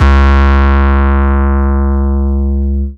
808 Kick 13_DN.wav